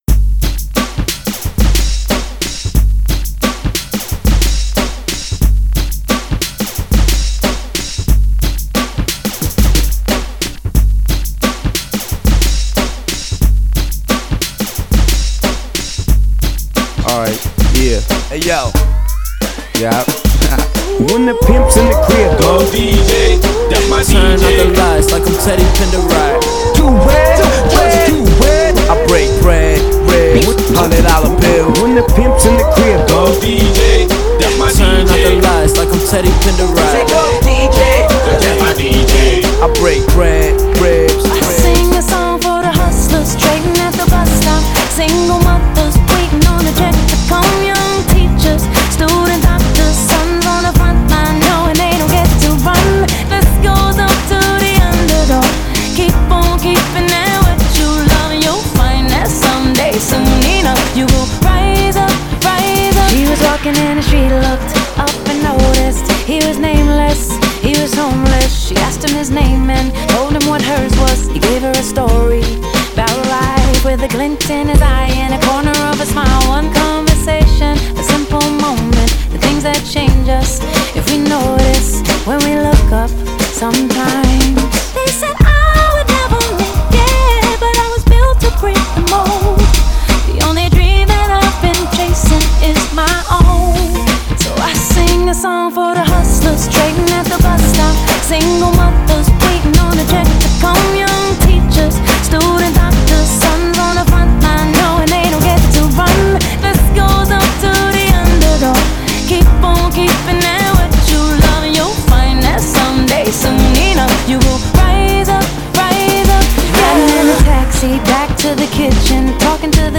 Genre: HIPHOP
Clean BPM: 104 Time